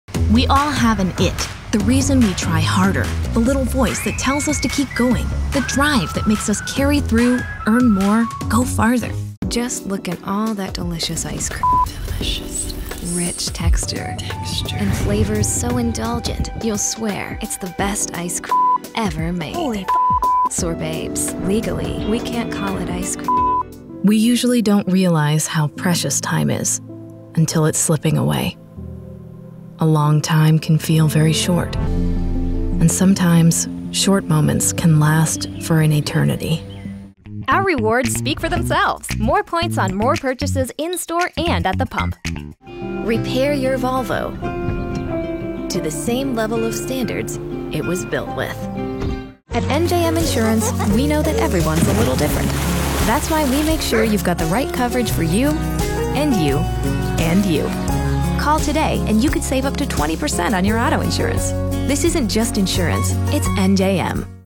Anglais (Américain)
Commerciale, Naturelle, Amicale, Chaude, Corporative
Commercial